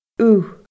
ugh /u/